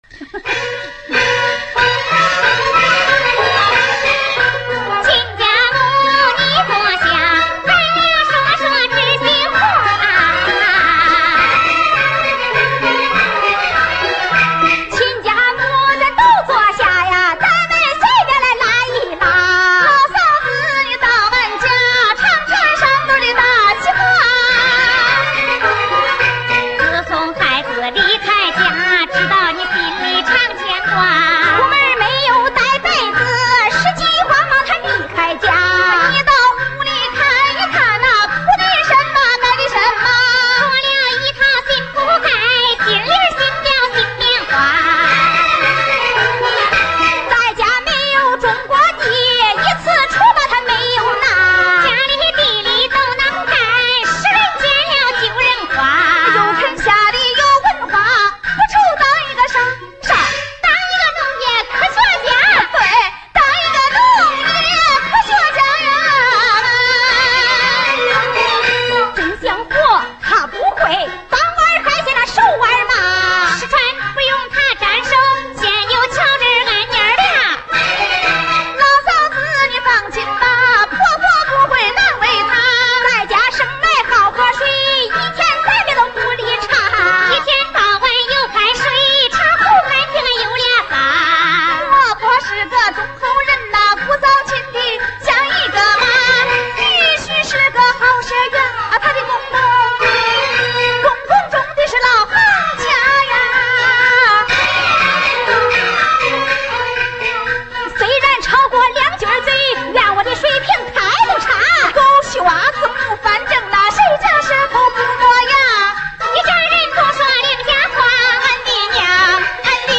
(1963年录音版本)